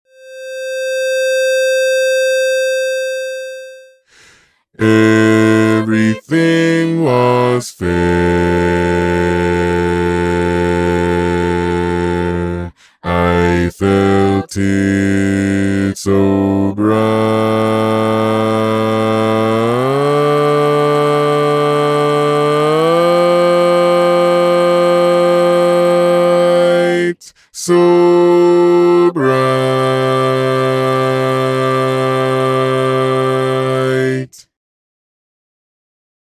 Key written in: C Major